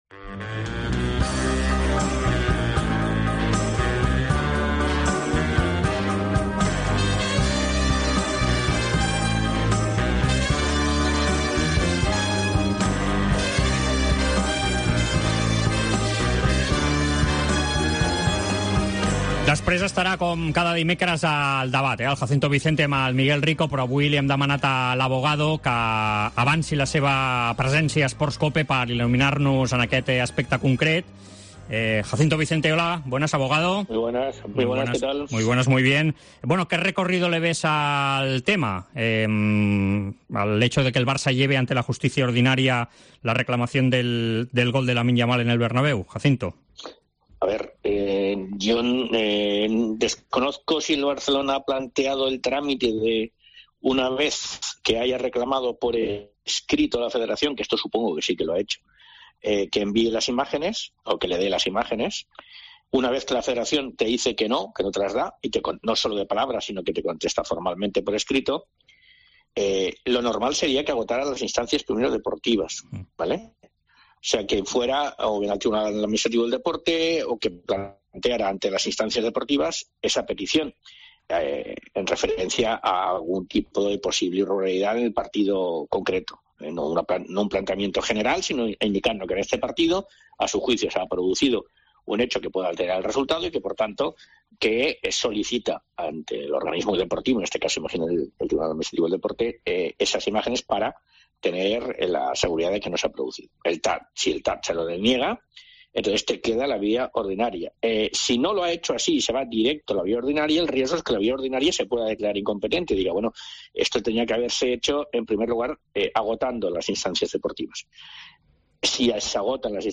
En Esports COPE hablamos con el abogado especialista en derecho deportivo, tras conocer que el Barça irá a la justicia ordinaria para recurrir el gol fantasma de El Clásico.